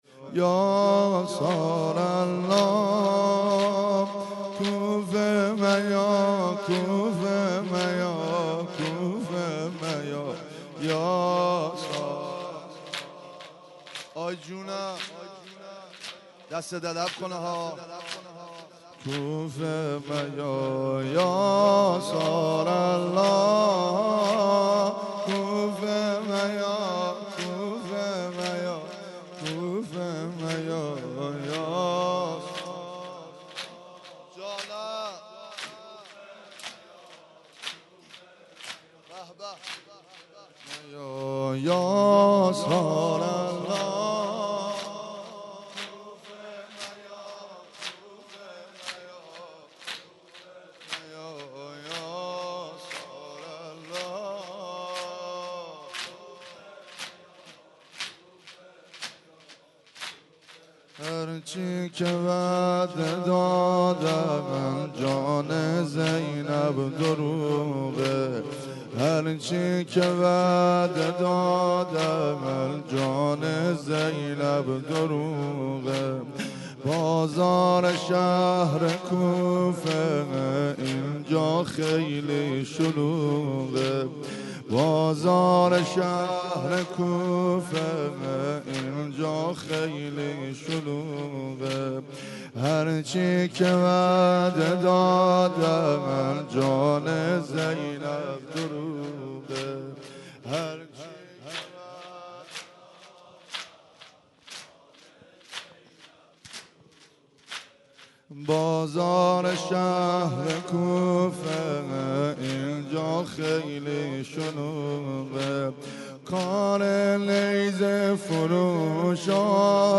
2- هرچی که وعده دادن - زمینه